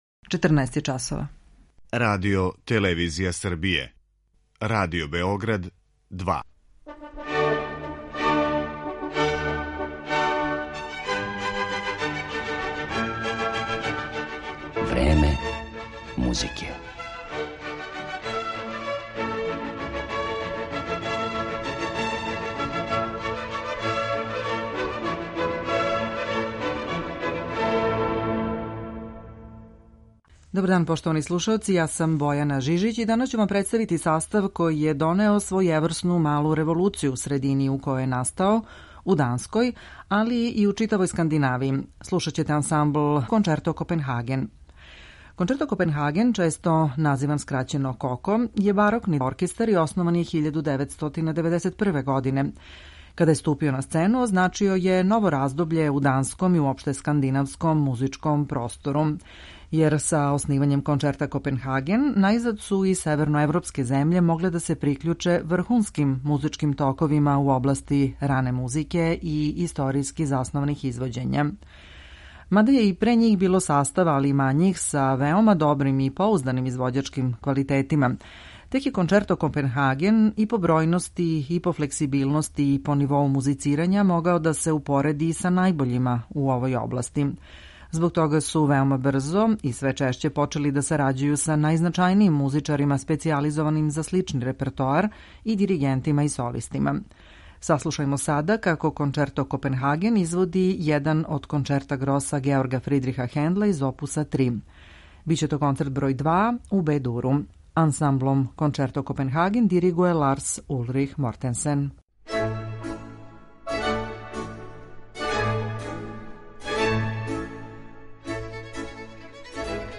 дански састав
чембалиста и диригент